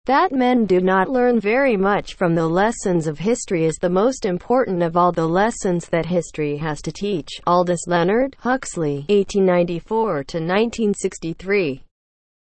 (Text-to-Speech by Sound of Text, using the engine from Google Translate)